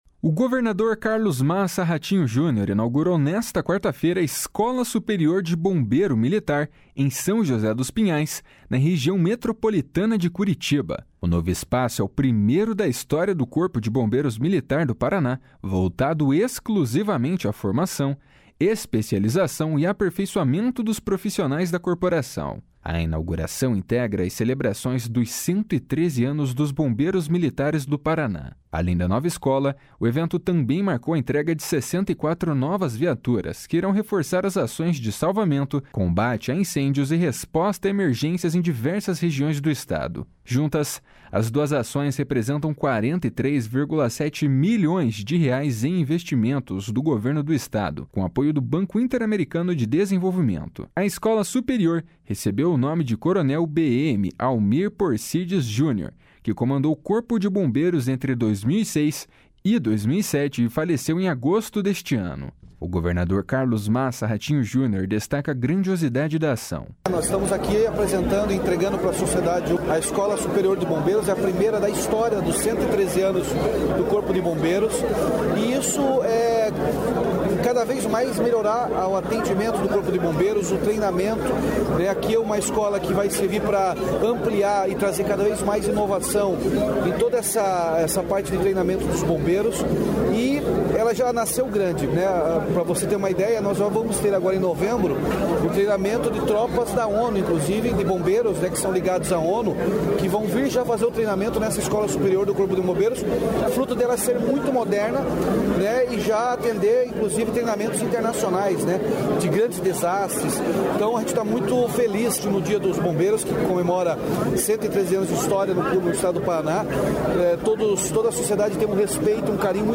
O governador Carlos Massa Ratinho Junior destaca a grandiosidade da ação.
O secretário da Segurança Pública, Hudson Leôncio Teixeira, destaca a expectativa que havia para esta inauguração.